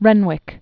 (rĕnwĭk), James 1818-1895.